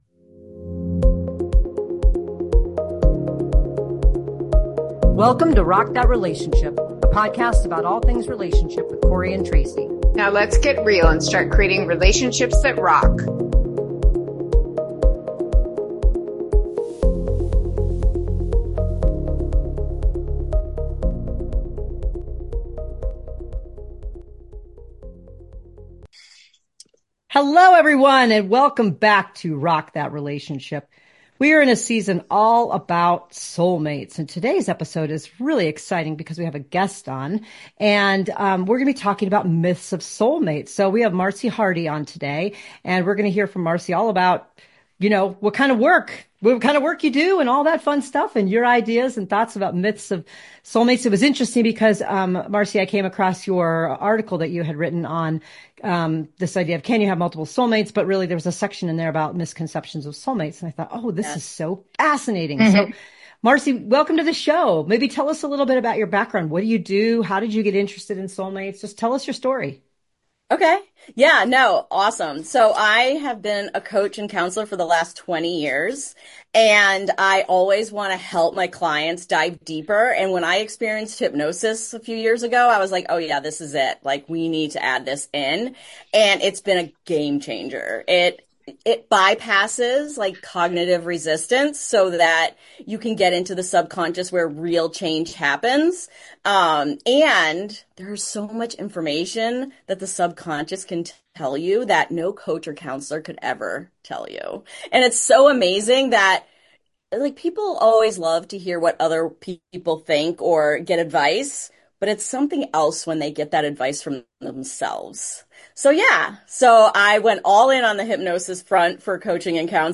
Tune in to this amazing conversation